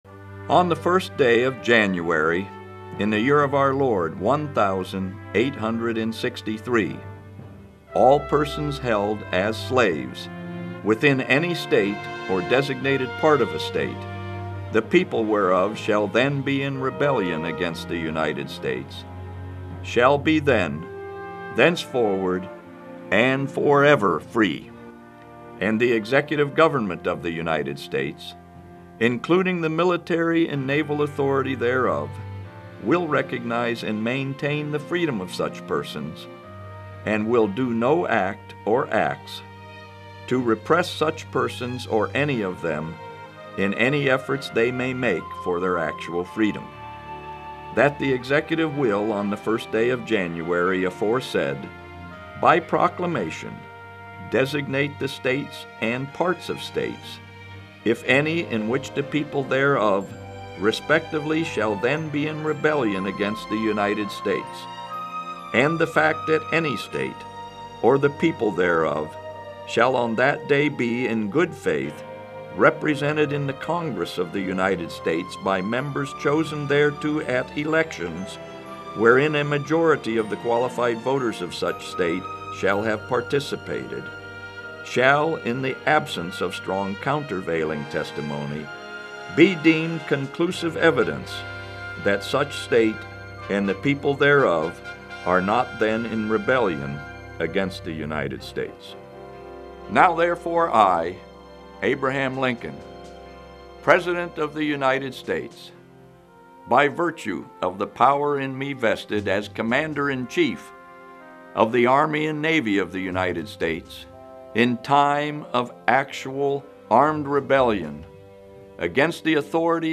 A dramatic reading of the Emancipation Proclamation, which paved the way for the abolishment of slavery and served as a rallying point in the midst of the Civil War.